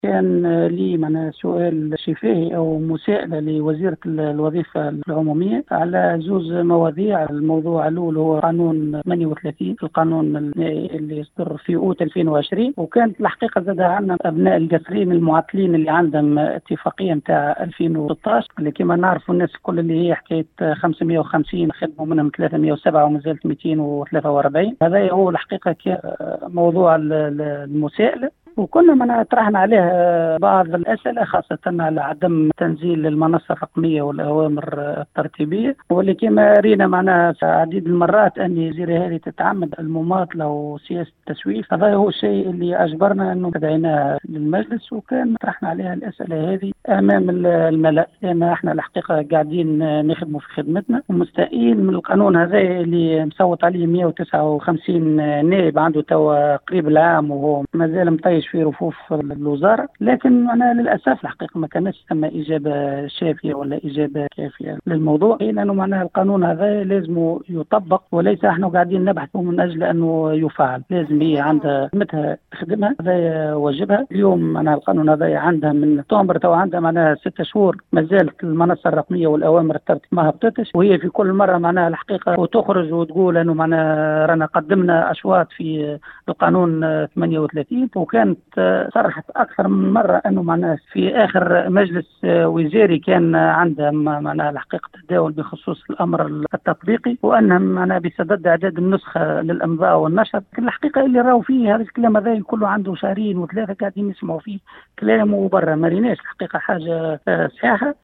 أفاد النائب عن جهة القصرين محمد الصالح لطيفي في تصريح لاذاعة السيليوم أف أم  اليوم الأربعاء 2 جوان 2021 ، أنّه قد كانت له مساءلة لوزيرة الوظيفة العموميّة بخصوص القانون عدد 38 الصادر في أوت 2020 ، وأيضا  بخصوص اتفاقيّة 2016 لأبناء الجهة المعطّلين .